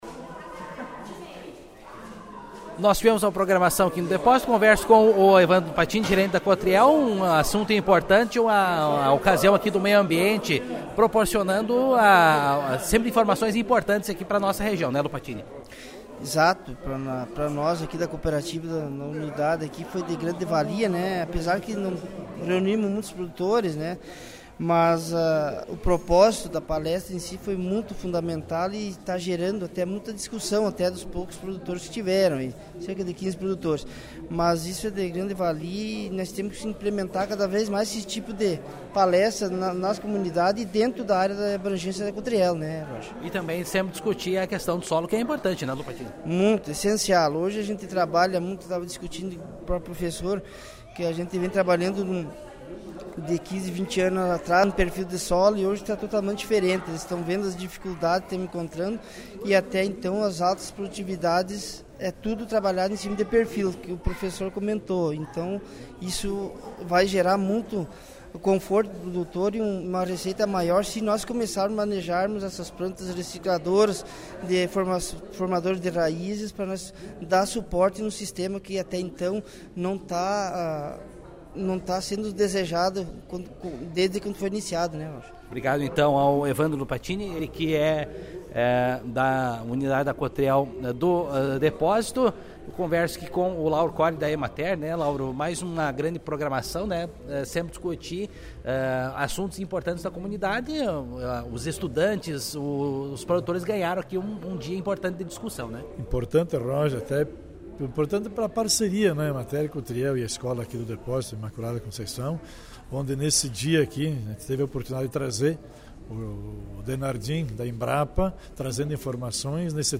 Nesta terça-feira, 27, tendo por local a Escola Imaculada Conceição do Depósito, Espumoso, aconteceu o Encontro Municipal do Meio Ambiente.
aqui as entrevistas feitas na ocasião.